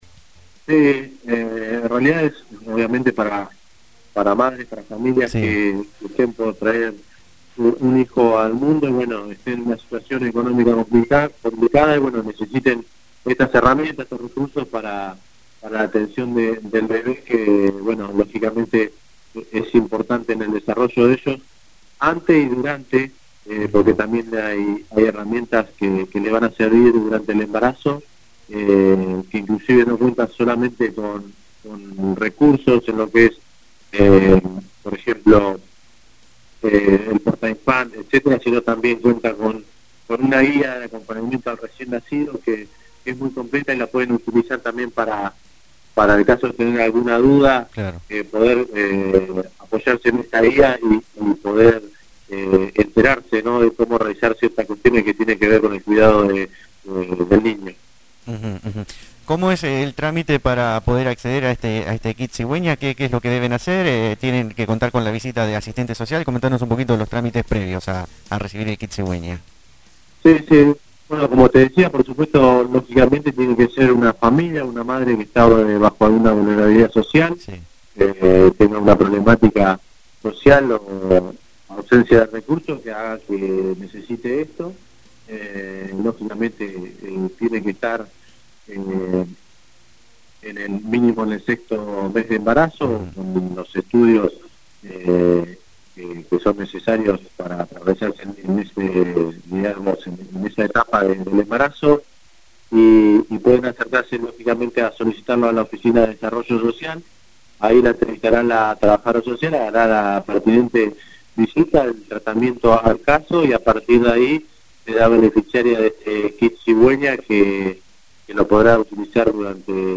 Al respecto esto decía Carlos Morelli, Secretario de Desarrollo Social a la Zfm.